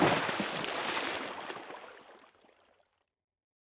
small-splash-4.ogg